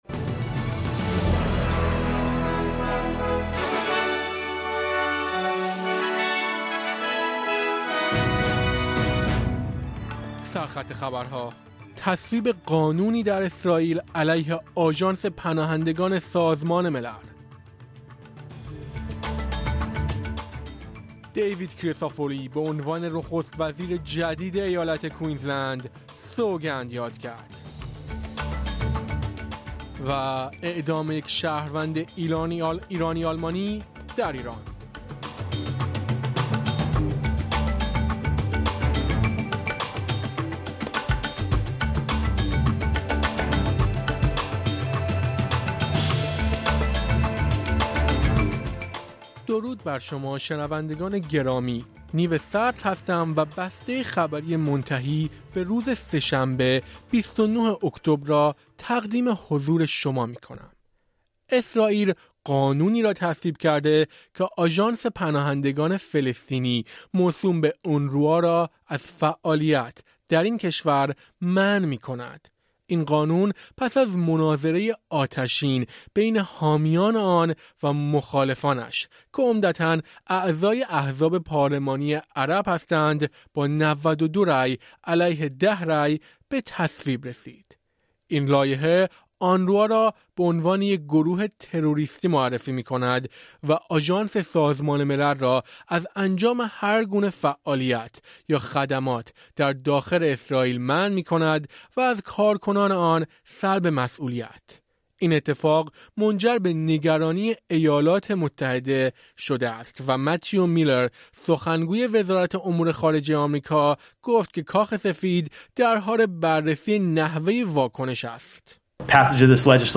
در این پادکست خبری مهمترین اخبار استرالیا، جهان و ایران در یک هفته منتهی به سه‌شنبه ۲۹ اکتبر ۲۰۲۴ ارائه شده است.